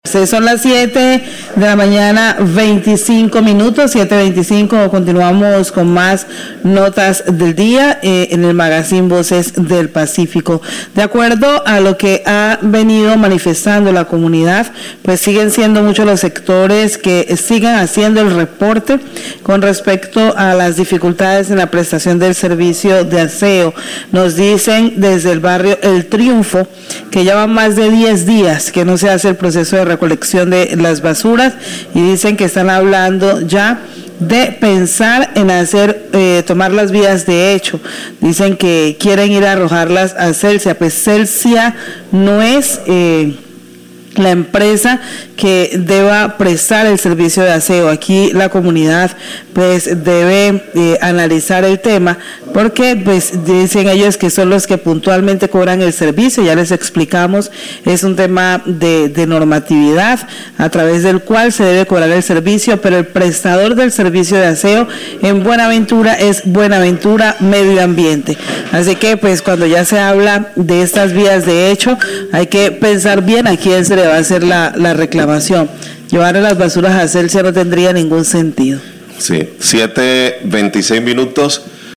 Radio
La comunidad manifiesta su inconformidad en cuanto a la prestación del servicio de aseo prestado por la empresa BMA y el cobro que se realiza por medio de la factura del servicio de energía; piensan en tomar vías de hecho, ir a dejar los residuos a la empresa Celsia. El locutor da claridad sobre el asunto informando que Celsia solo es un recaudador, no es responsable del servicio.